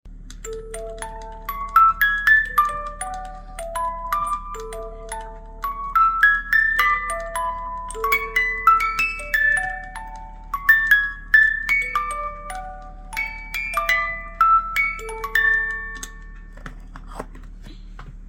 Cajita musical de manivela